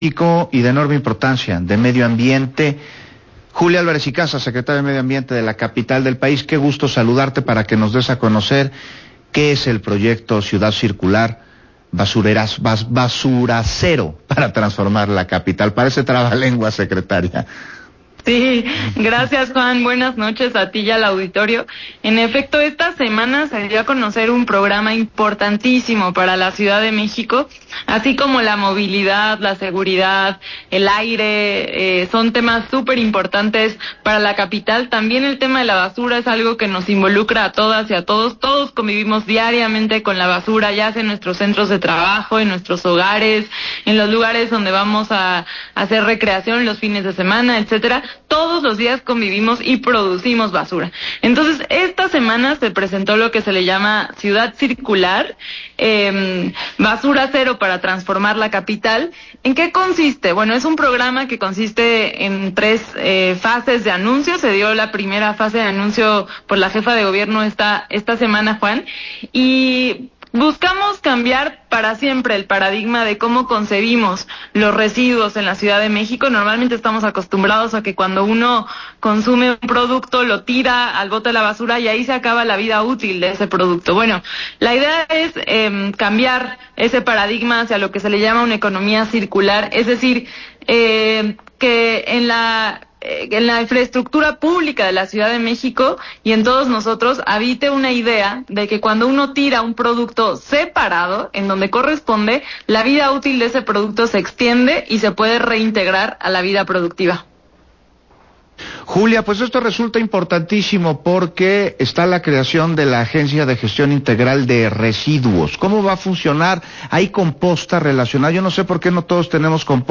Entrevista a Julia Álvarez Icaza, secretaría del Medio Ambiente de la CDMX, sobre el proyecto Ciudad Circular, Basura Cero